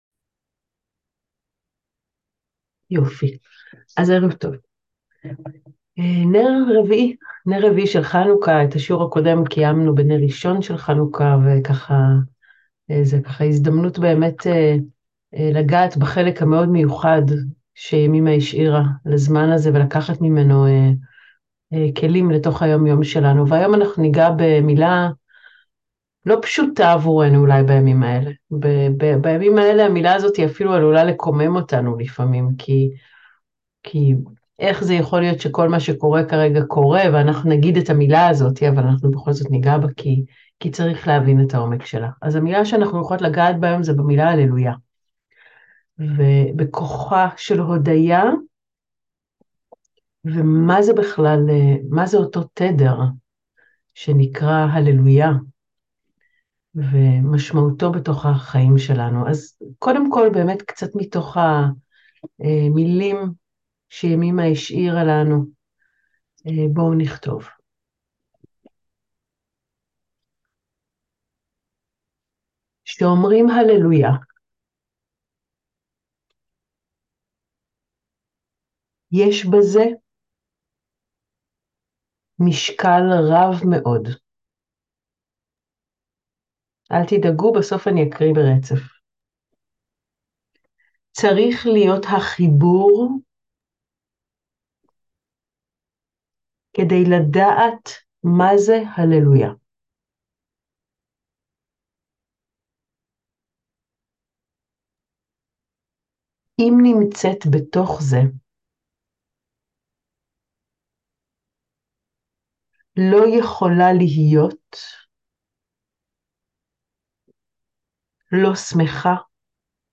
הלומדות שיעור לחנוכה #2